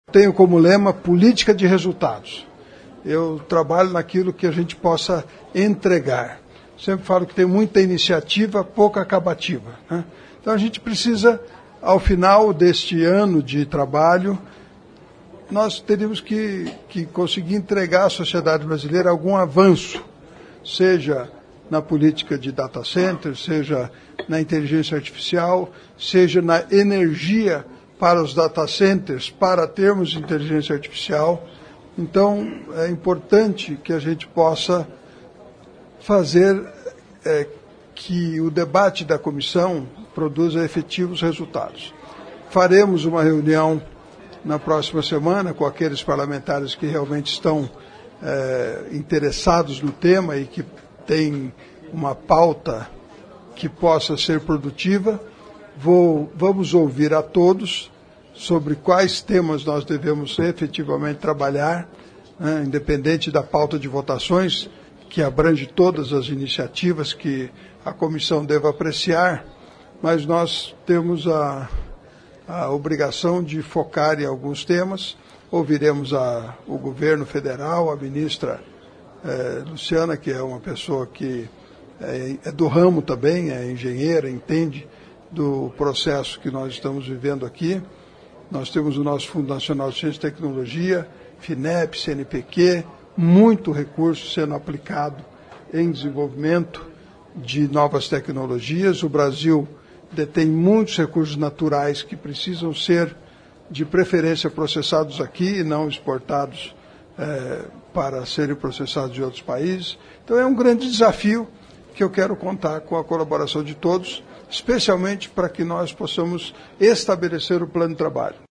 No discurso após a eleição, Ricardo Barros disse que o foco será inteligência artificial, data centers e geração de energia.